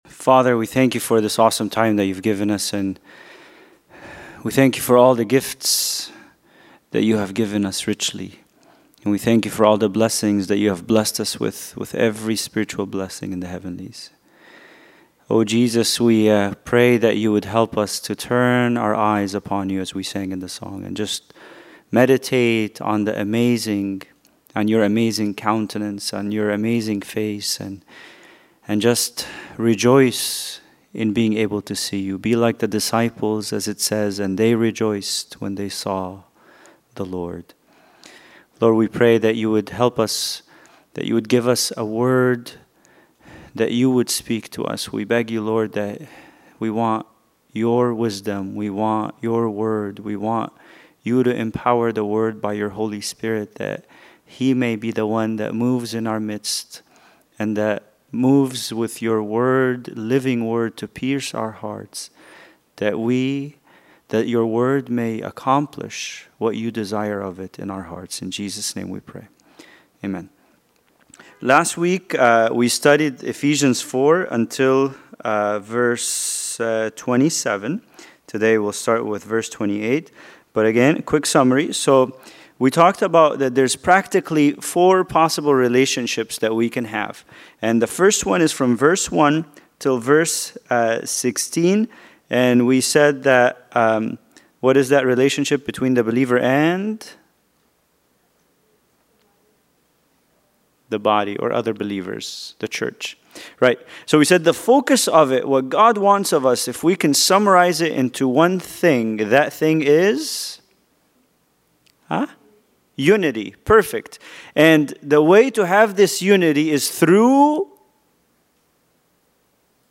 Bible Study: Ephesians 4:28